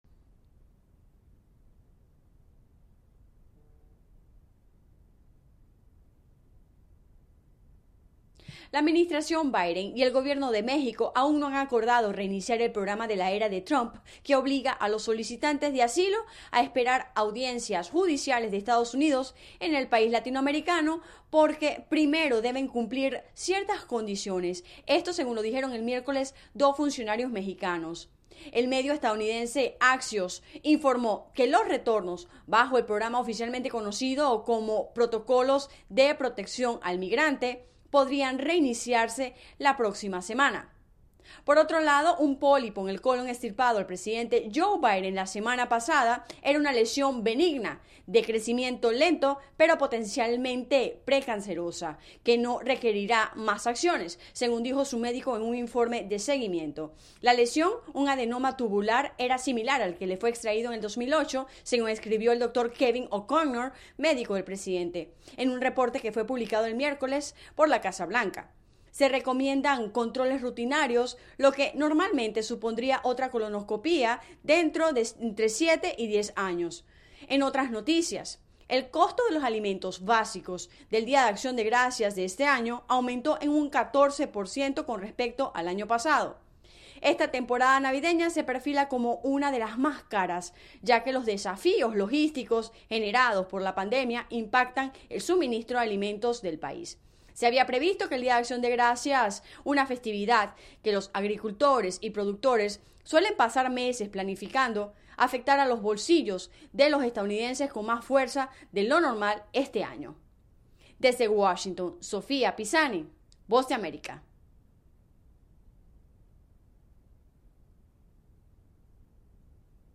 La agenda del día [Radio]